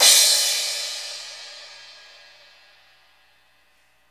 CYM XCRASH0J.wav